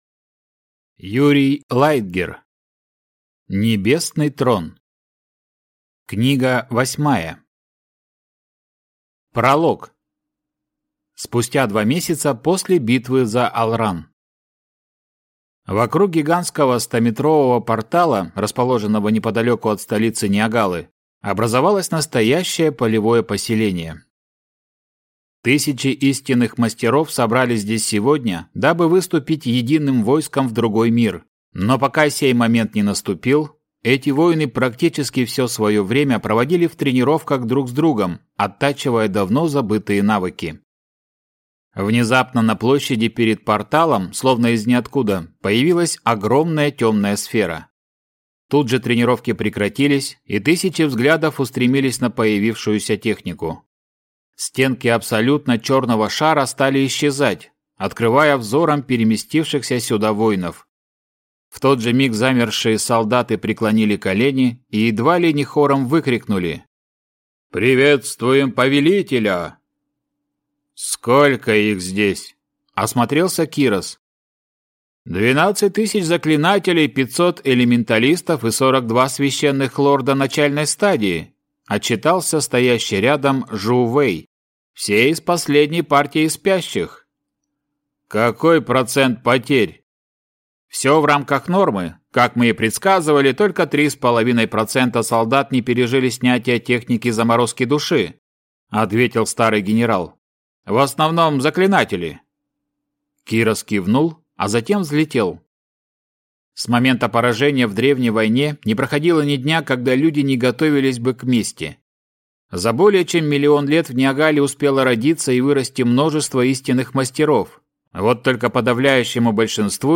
Аудиокнига Небесный Трон. Книга 8. Часть 1 | Библиотека аудиокниг